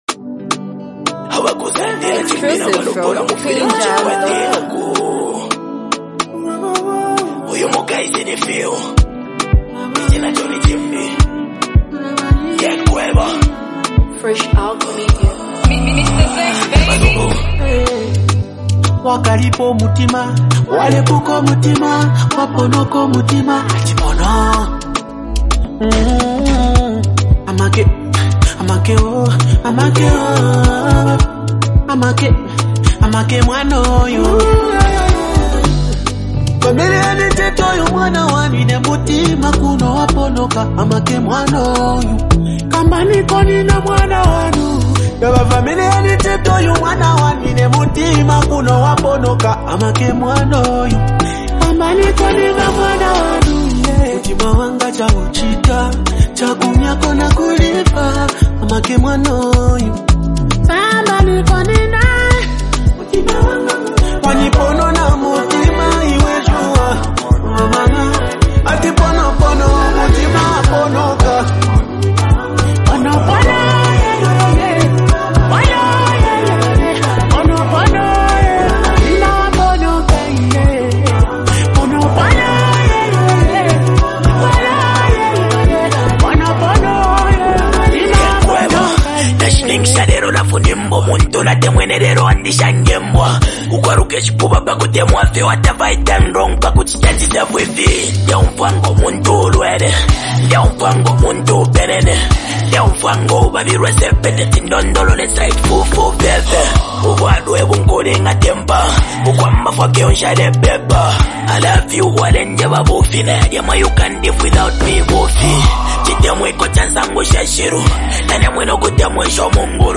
reflective and socially conscious song